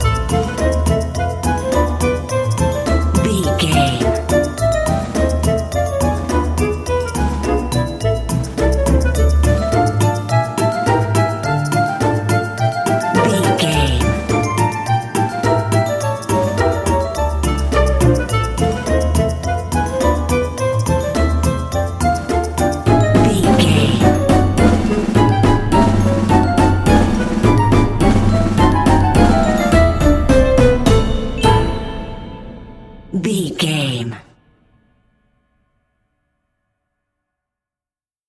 Aeolian/Minor
percussion
strings
double bass
synthesiser
circus
goofy
comical
cheerful
perky
Light hearted
quirky